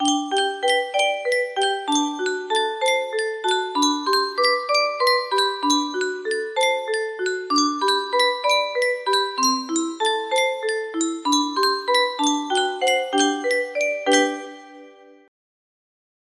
Yunsheng Music Box - Little Bo-Peep 2791 music box melody
Full range 60